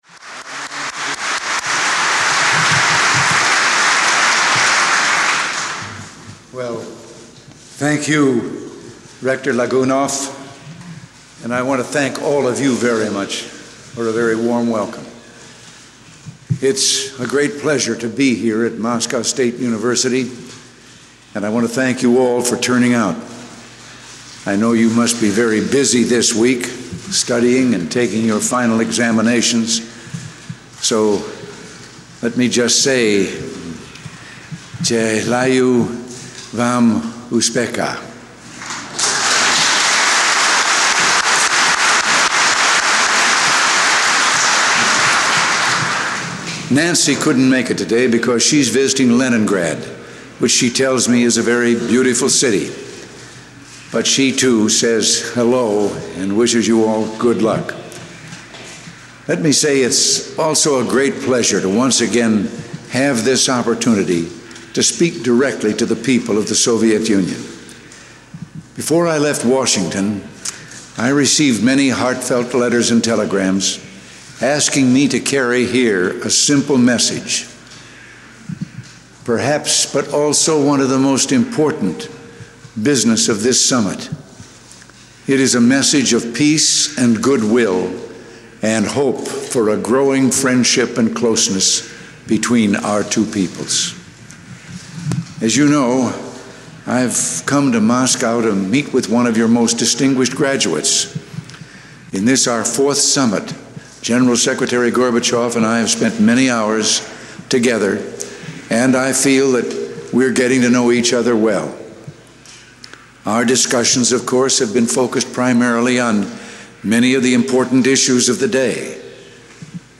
Ronald Reagan - Moscow State University Speech
ronaldreaganmoscowuniversityARXE.mp3